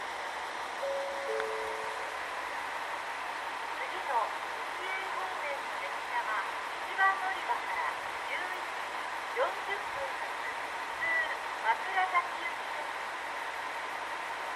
この駅では接近放送が設置されています。
１番のりば指宿枕崎線
接近放送普通　枕崎行き予告放送です。